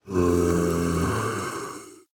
Minecraft Version Minecraft Version snapshot Latest Release | Latest Snapshot snapshot / assets / minecraft / sounds / mob / husk / idle3.ogg Compare With Compare With Latest Release | Latest Snapshot